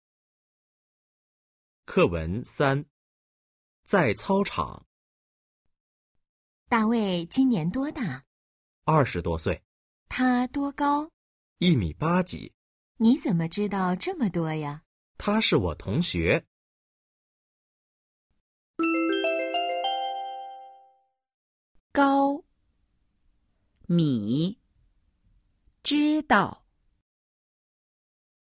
Hội thoại 3：在操场 – Ở sân thể thao  💿 02-03